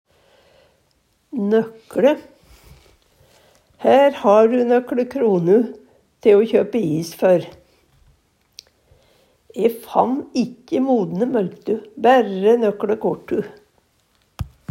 DIALEKTORD PÅ NORMERT NORSK nøkLe nokon, nokre Eksempel på bruk Her ha du nøkLe kronu te o kjøpe is før.
Hør på dette ordet Ordklasse: Determinativ (mengdeord) Attende til søk